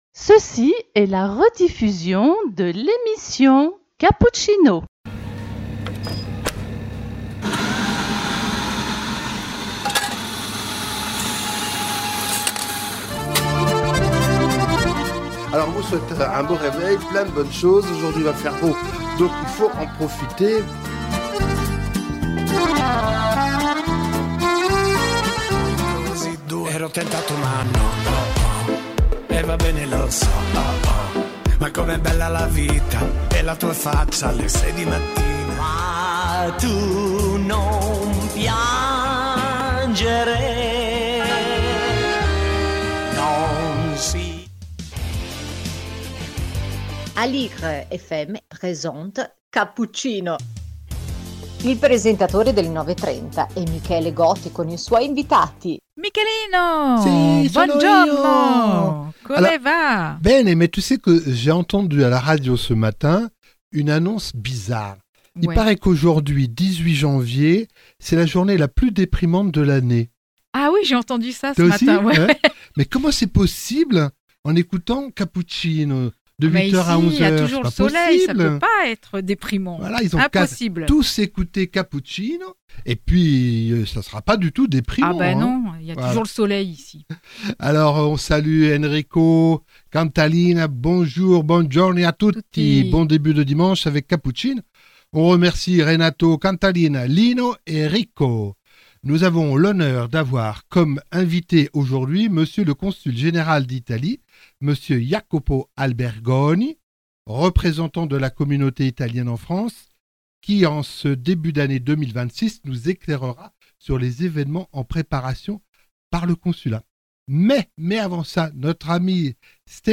Cappuccino # 18 janvier 2026 - invité monsieur Jacopo Albergoni, consul général d'italie à Paris